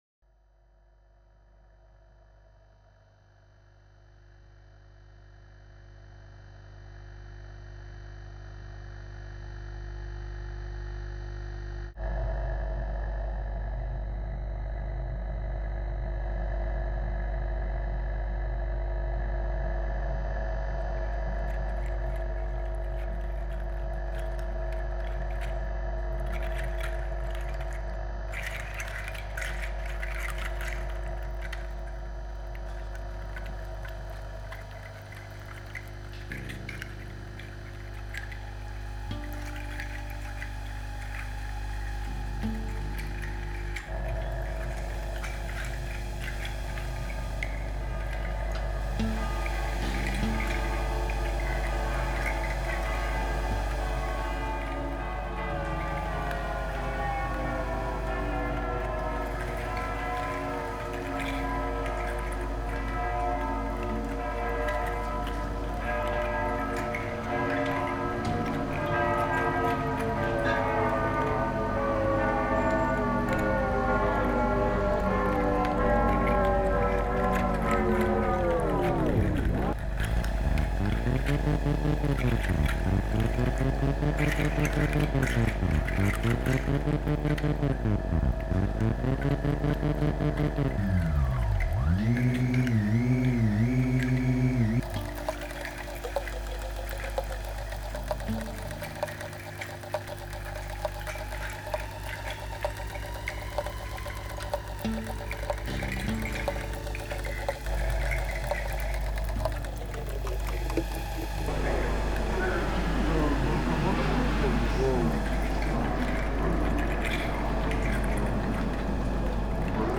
The sound material consists of the acoustic and electromagnetic sounds of the city where the project is presented.
Some examples of mixes created by visitors of OSCILLATIONS
for Midi controller, Apple computer, acoustic and electromagnetic sounds